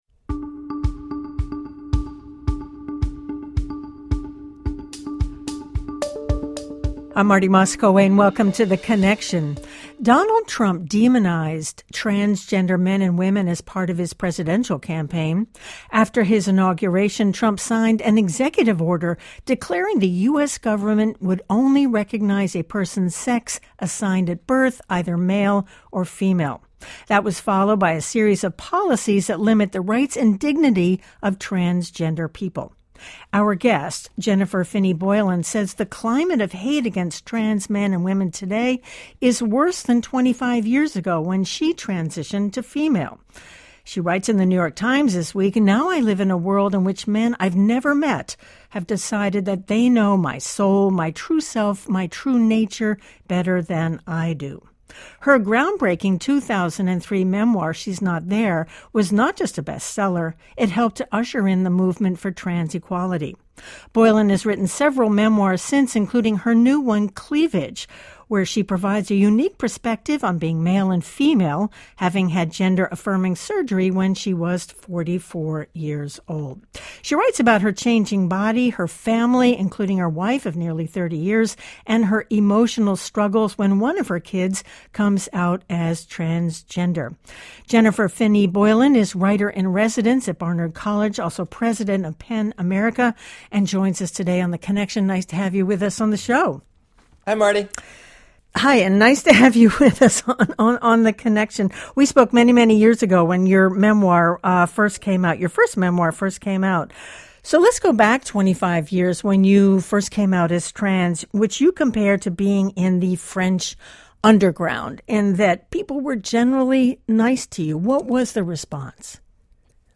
Philadelphia Orchestra pop-up quartet in Lyon, France (video) - WHYY
While touring in Lyon, France, members of the Philadelphia Orchestra performed pop-up concerts in public places.
violin
cello
viola) played Mendelssohn’s String Quartet No. 1, Op. 12 in Lyon’s Place Louis Pradel.